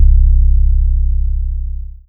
DOGYBAG BASS.wav